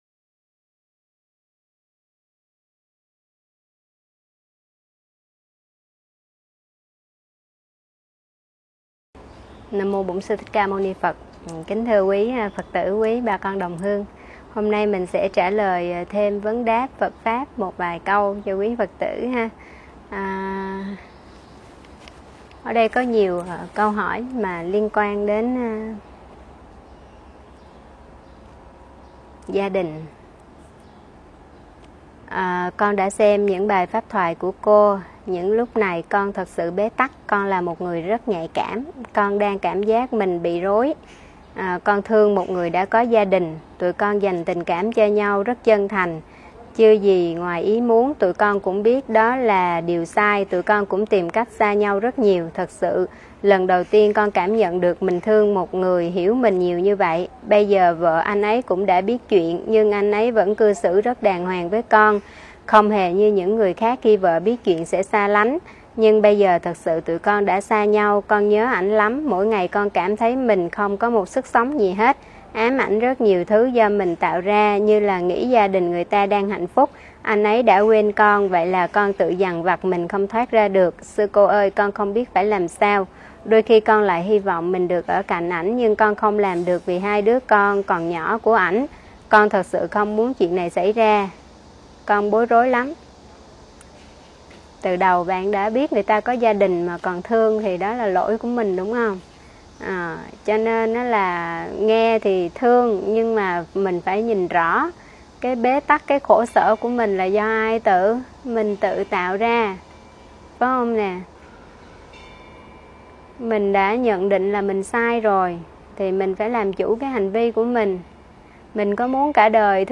Vấn Đáp Phật Pháp Nghiệp vô gia cư, đốt vàng mã